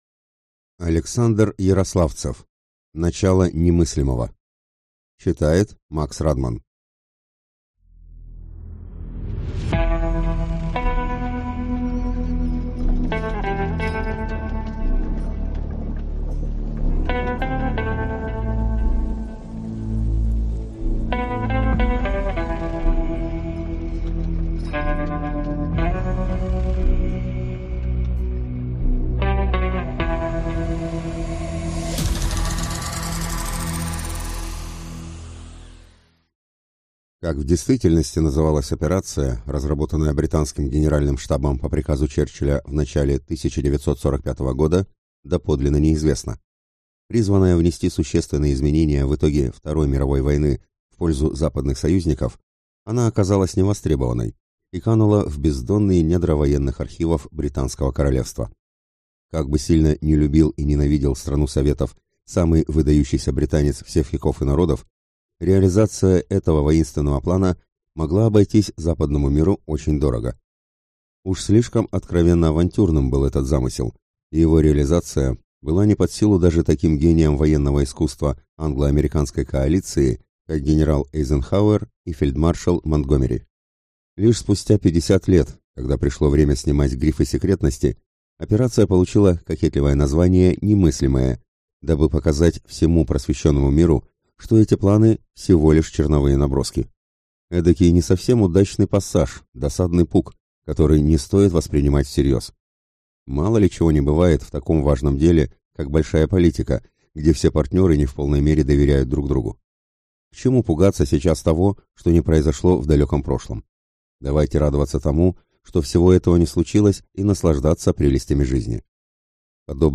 Аудиокнига Начало «Немыслимого» | Библиотека аудиокниг